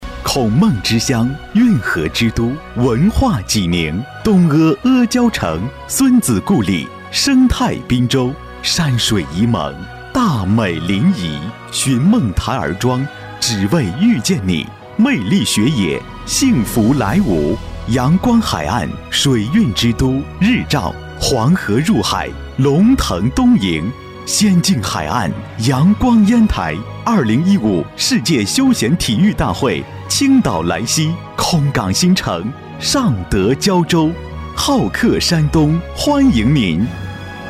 年轻时尚 金融保险 描述： 下载 现在咨询 安邦保险男108号